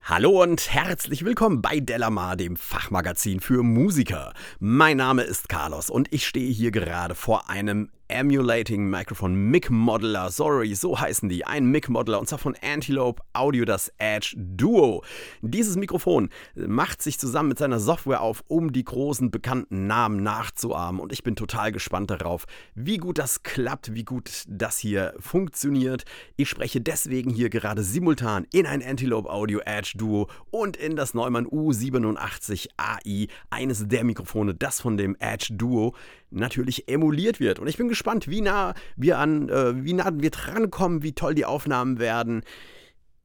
Ich habe zudem auch das Neumann U87ai so nah wie möglich bei den Aufnahmen positioniert, damit Du auch hier die Nähe selbst hören kannst.
Neumann U87 Original (Sprache)
aa_edge_duo__06_u87_orig.mp3